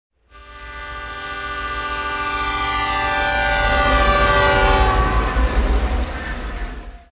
sound-train.mp3